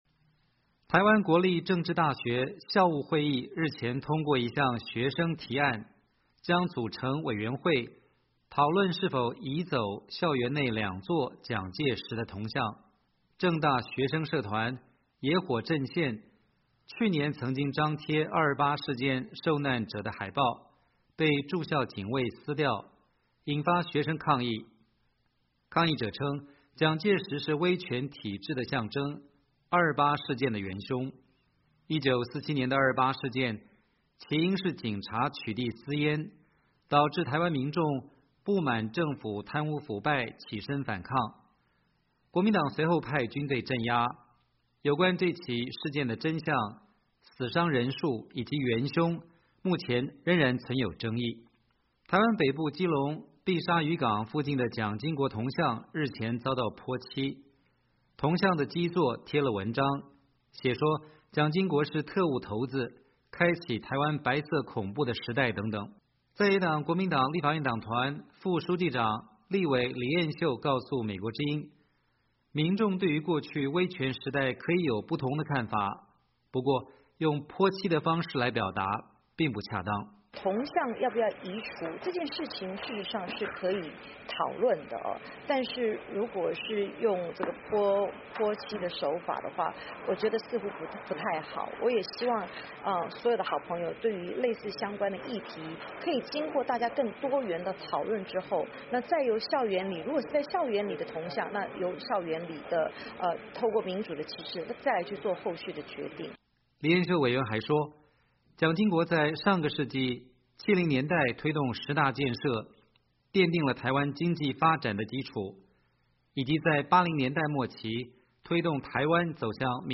在野党国民党立法院党团副书记长、立委李彦秀告诉美国之音，民众对于过去威权时代可以有不同的看法，不过用泼漆的方式来表达并不恰当。
时代力量党立法委员徐永明接受美国之音采访表示，立法院司法及法制委员会已经通过了“促进转型正义条例”的草案，执政党应该继续推动完成立法。